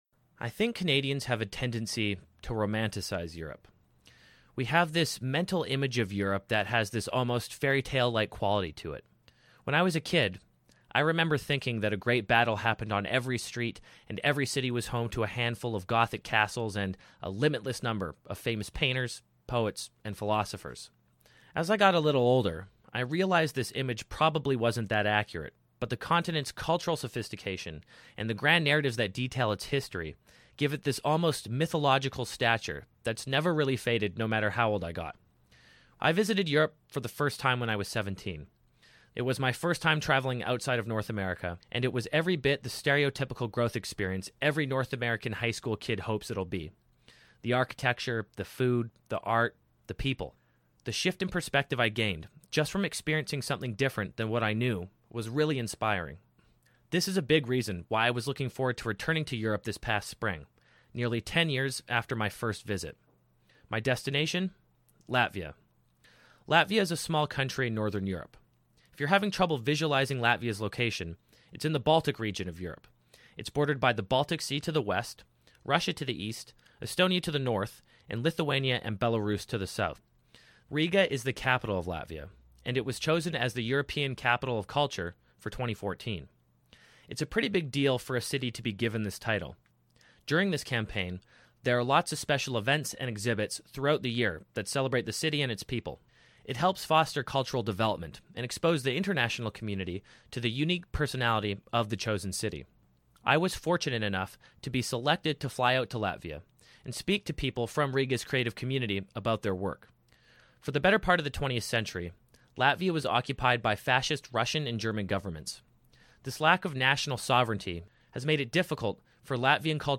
Travel documentary